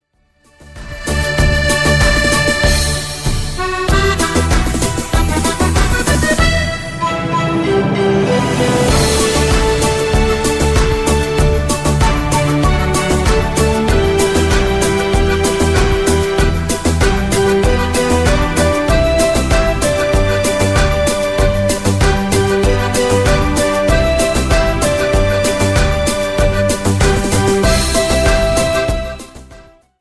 CUMBIA  (3.50)